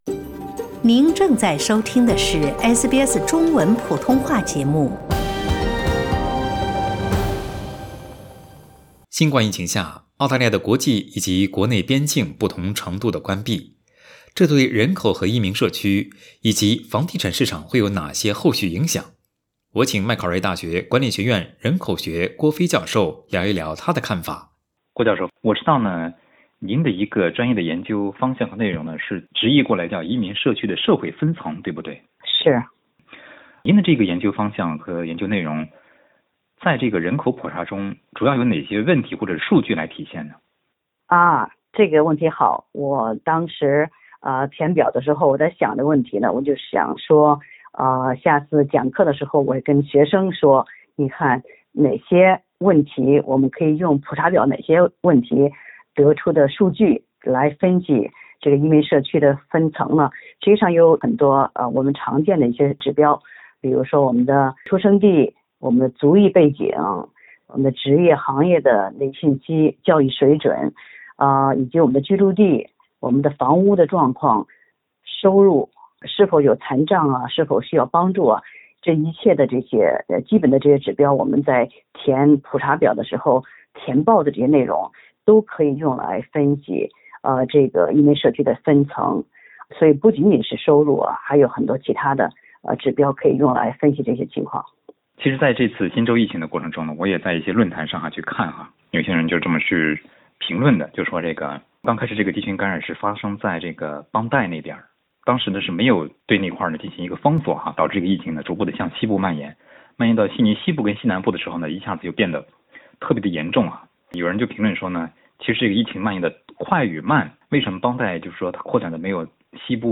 在采访中